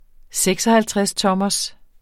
Udtale [ ˈsεgsʌhaltʁεsˌtʌmʌs ]